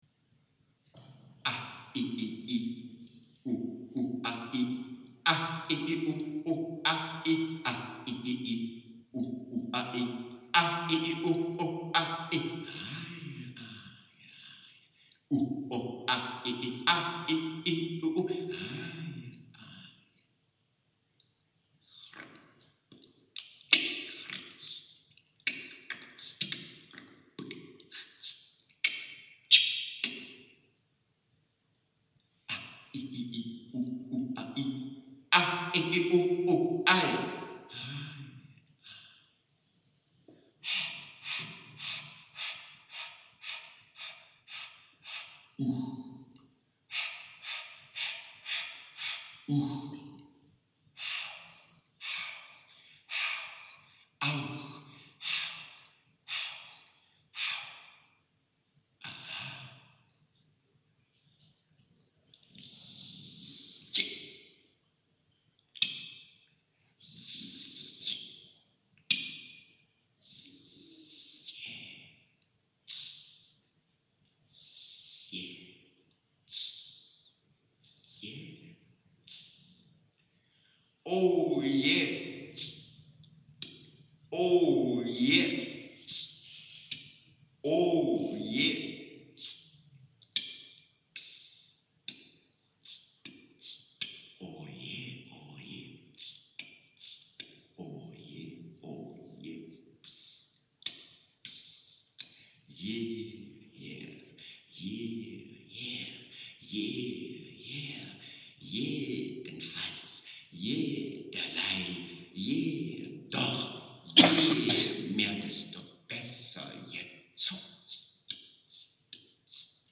Aufnahmen von der Lesung in Leipzig mit Lautgedichten
Lautgedicht 4